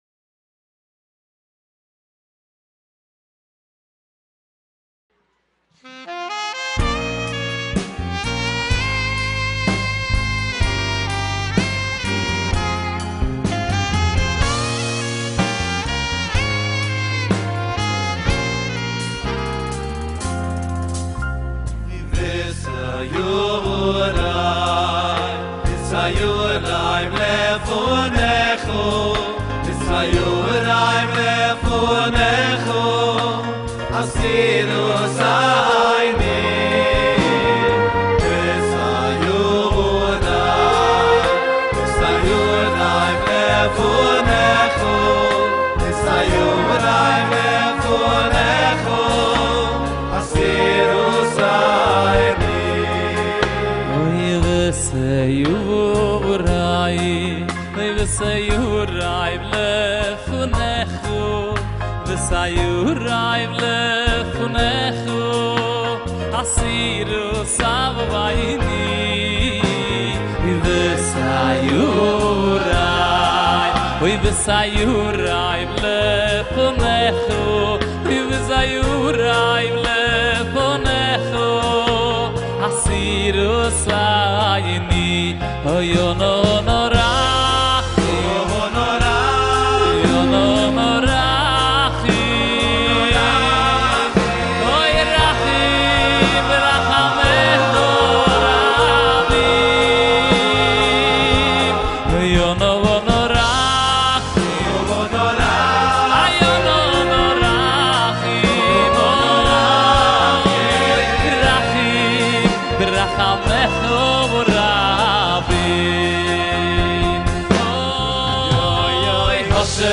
צפו בביצוע באירוע באולמי עטרת אברהם